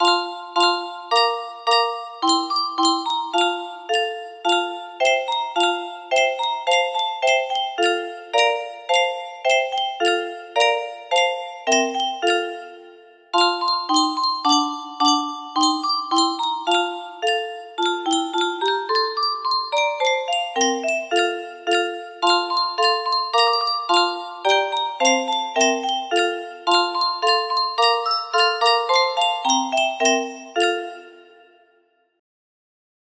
bro bro brille music box melody